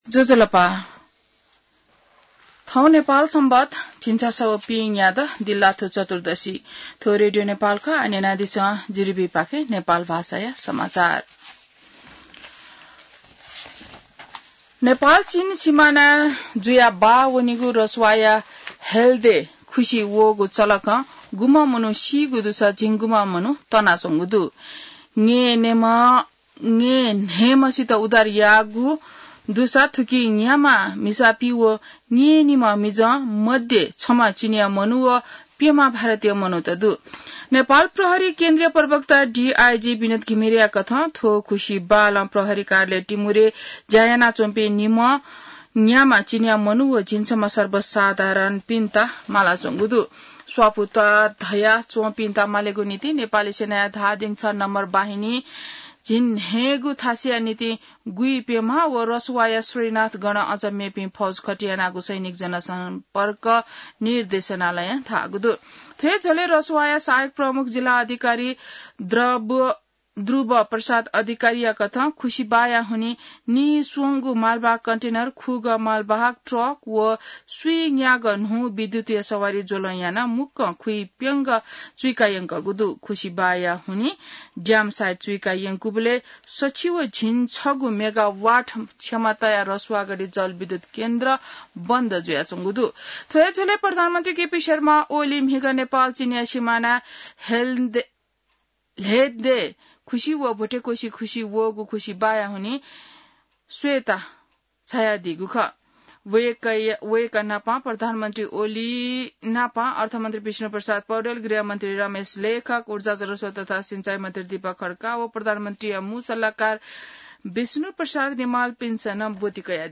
नेपाल भाषामा समाचार : २५ असार , २०८२